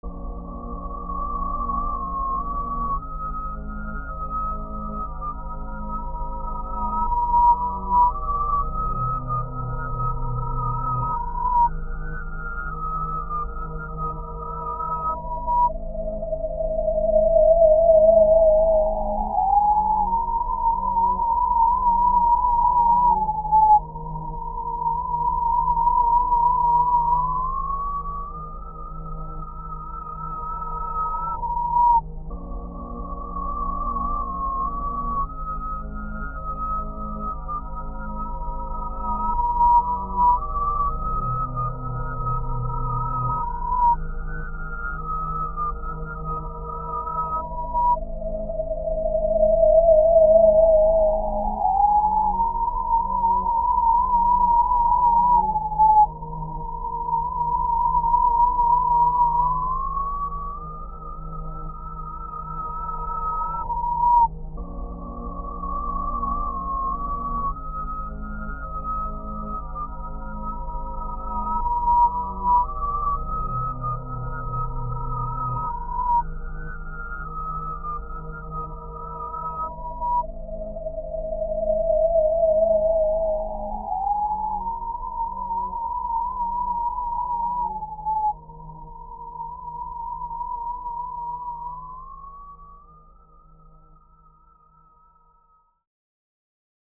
the ethereal,